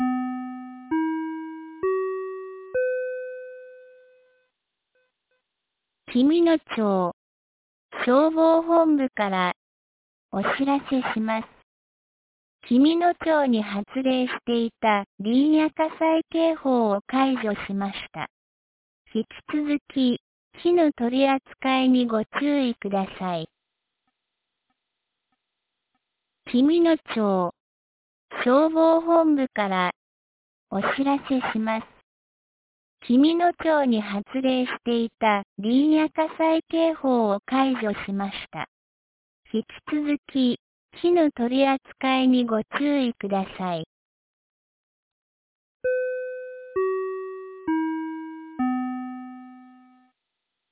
2026年01月09日 10時01分に、紀美野町より全地区へ放送がありました。